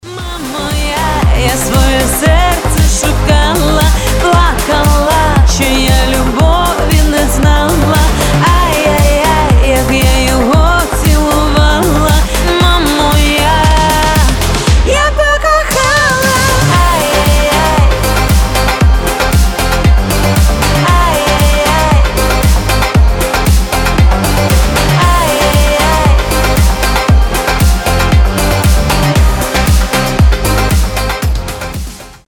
• Качество: 320, Stereo
поп
громкие
женский вокал
Хорошая украинская поп-музыка.